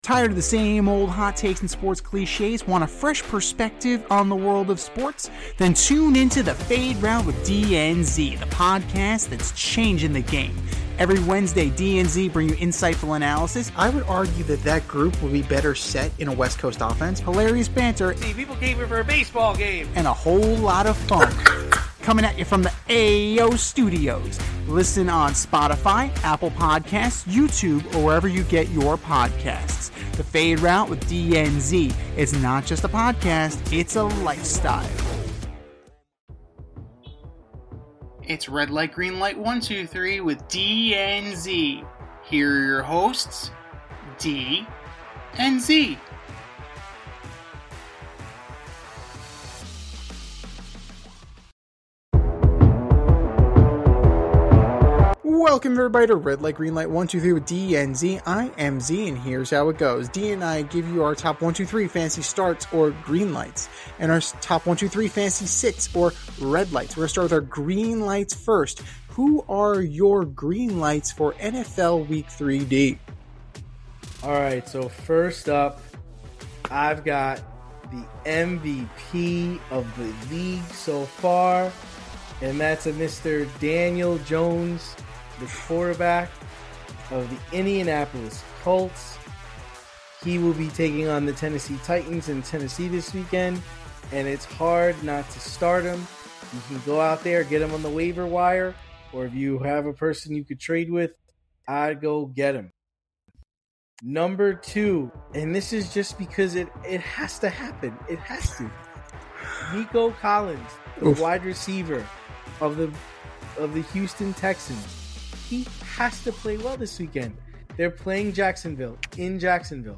two veteran sports aficionados and lifelong friends